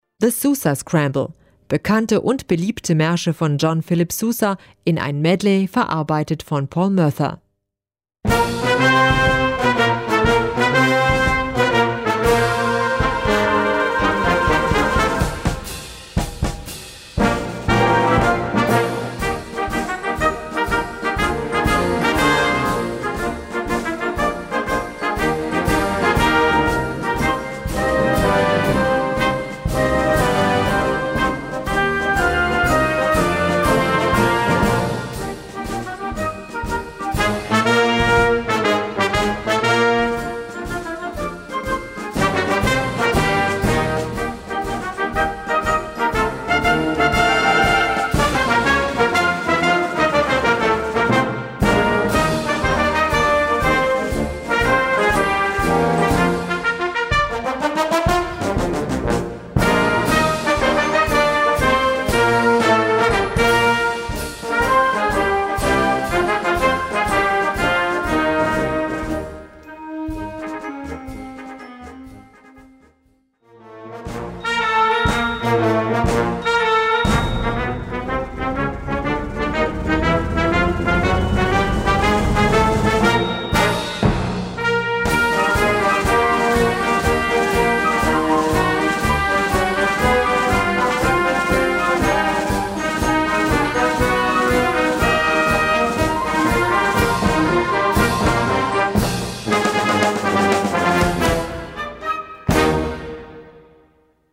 Gattung: Marsch-Medley
Besetzung: Blasorchester